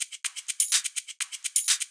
Live Percussion A 17.wav